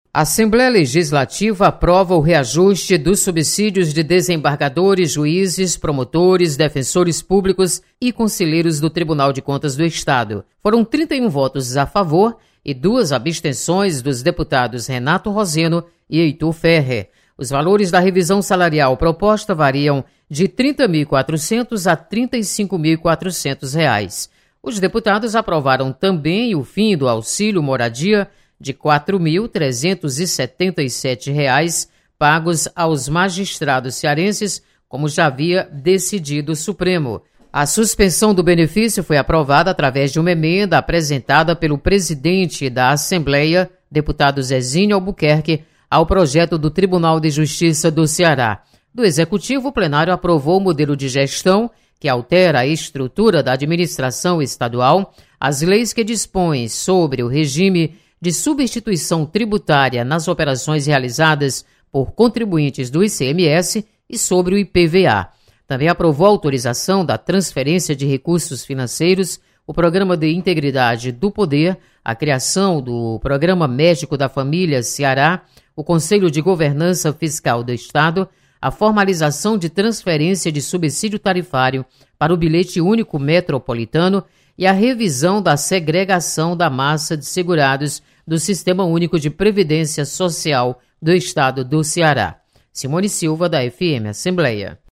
Aprovado reajuste salarial para juízes, promotores, defensores públicos e conselheiros do TCE. Repórter